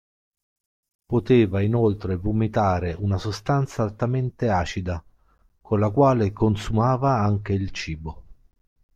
al‧ta‧mén‧te
/al.taˈmen.te/